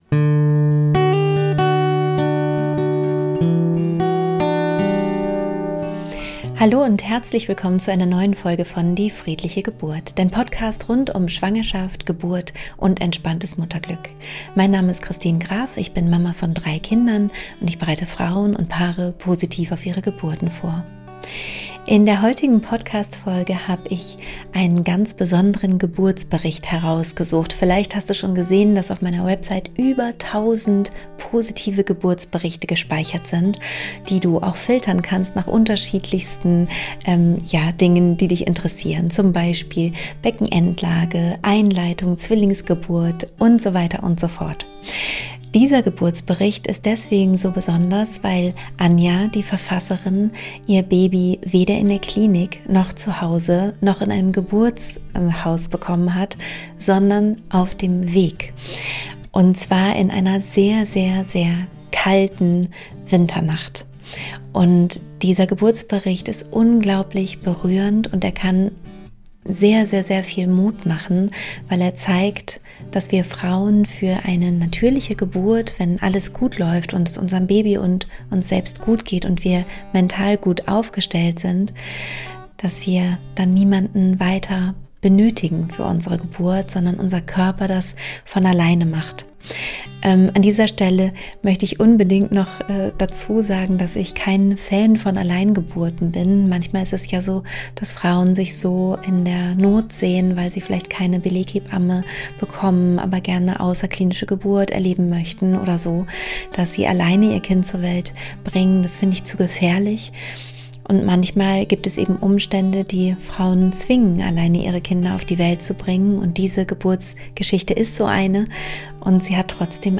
In dieser Podcastfolge lese ich passend zur Jahreszeit einen ganz